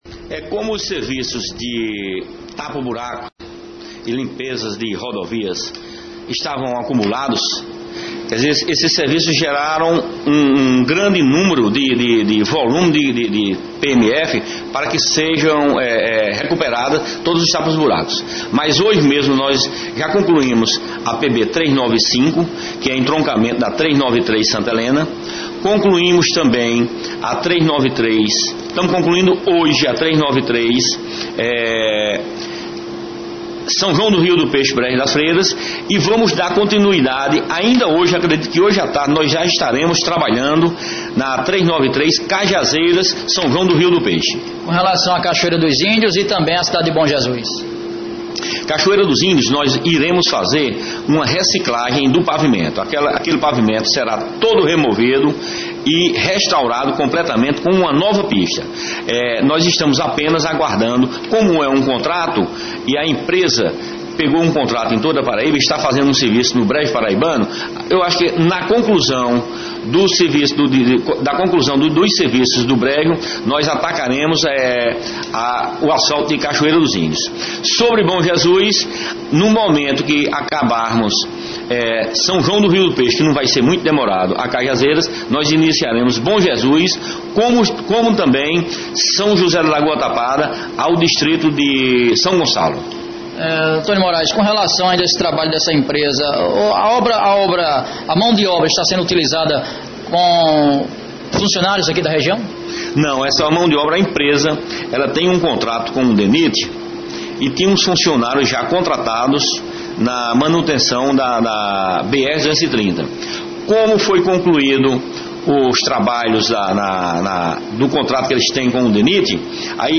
Em entrevista ao Programa Rádio Vivo da Alto Piranhas desta quinta-feira(10) o chefe do DER-PB(Departamento de Estradas e Rodagens da Paraíba) Antônio Morais destacou os trabalhos que estão sendo feitos nas rodovias estaduais pelo Governo do Estado nos últimos dias.